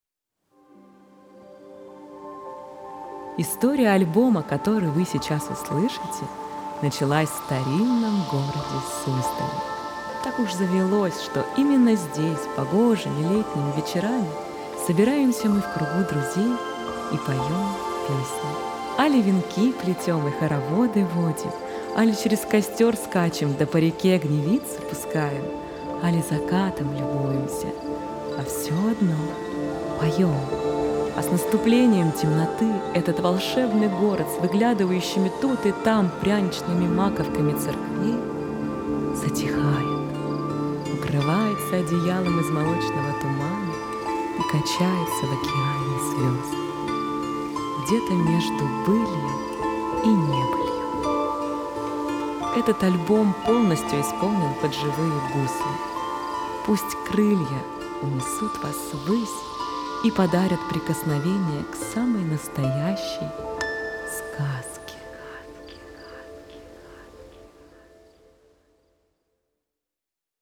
Жанр: Народная музыка